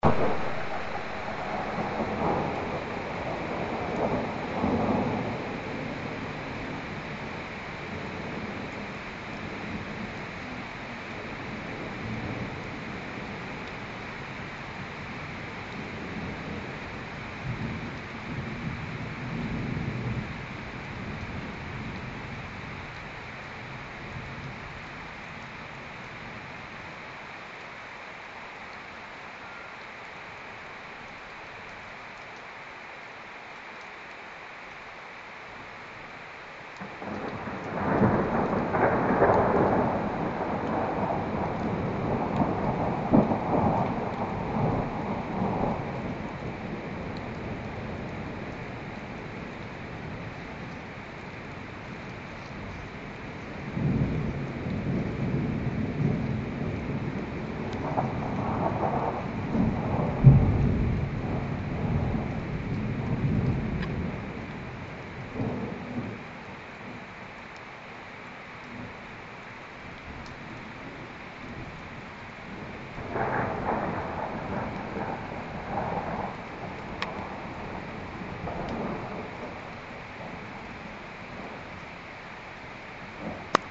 Гроза
вскоре началась гроза, раскаты грома сливались в один сплошной гул.
Мезмай, Скальная Полка Орлиной Скалы
groza.MP3